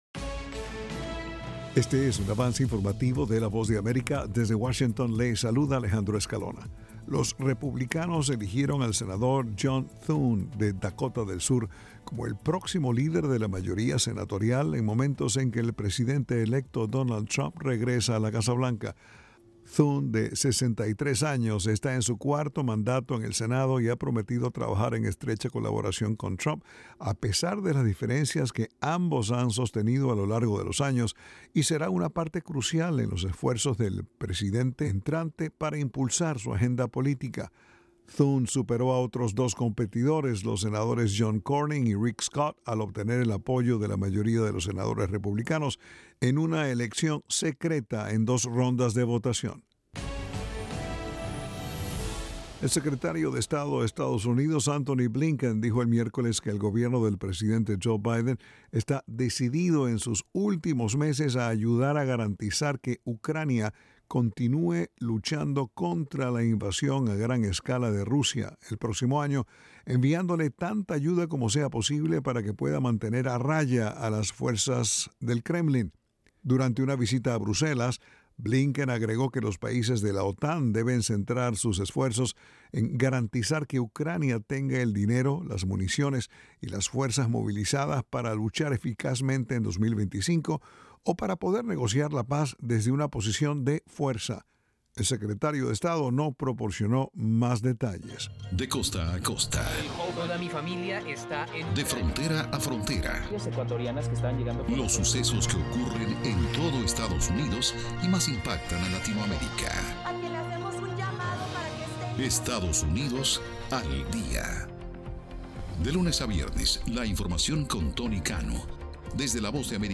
El siguiente es un avance informativo de la Voz de América.